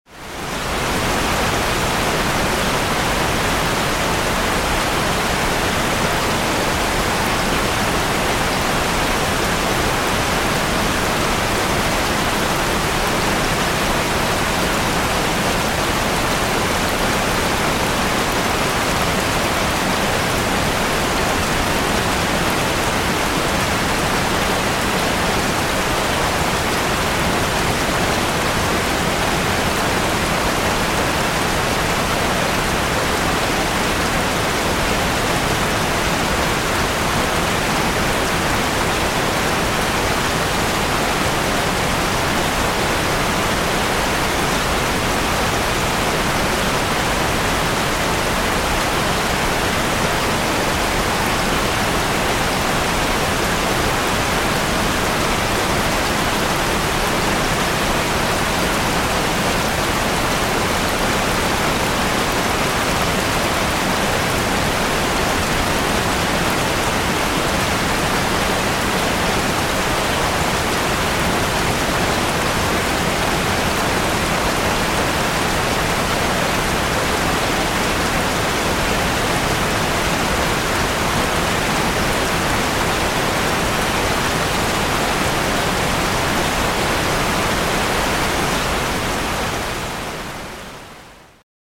Все дожди записаны без грома, это просто дожди, которые можно скачать для звукового оформления любого контента. Качество записей высокое.
Ливень, отвесно льет, ветра нет — 01 мин 31 сек